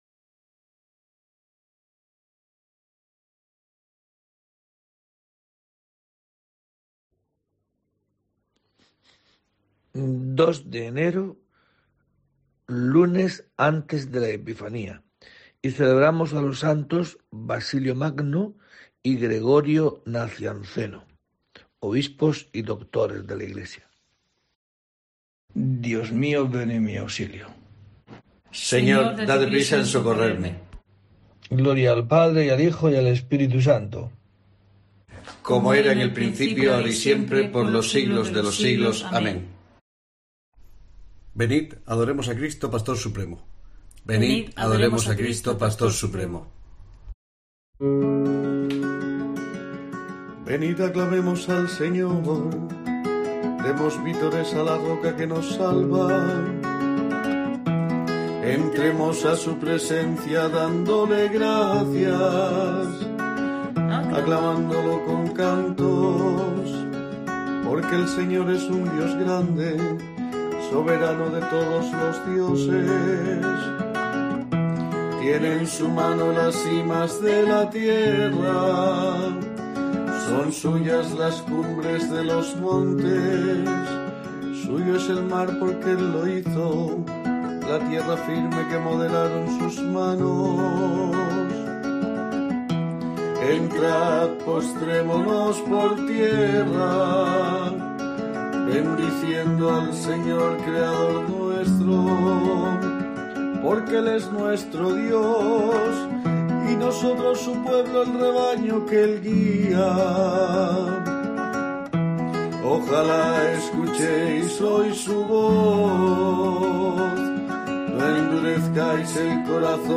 02 de enero: COPE te trae el rezo diario de los Laudes para acompañarte